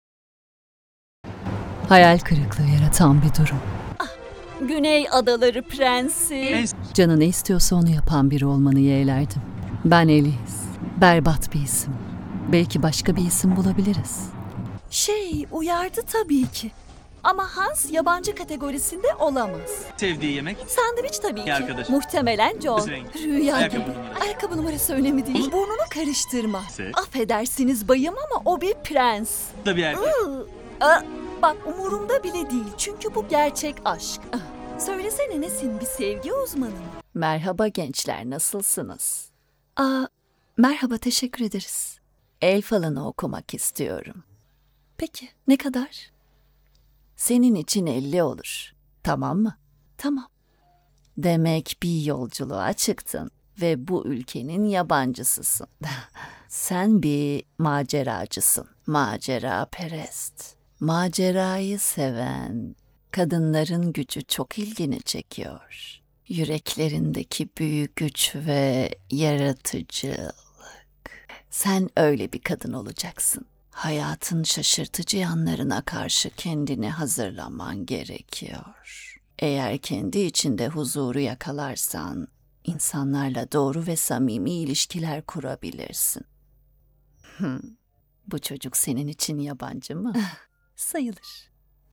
Profundo, Natural, Cool, Cálida, Empresarial
She has her own professional home studio.